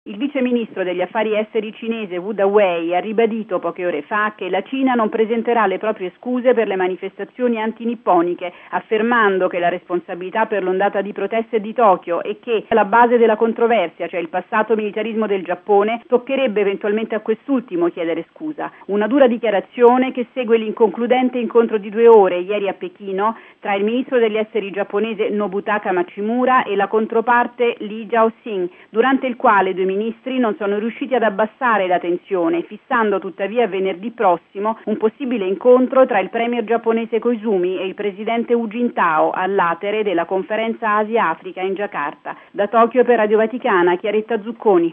Il servizio dalla capitale giapponese: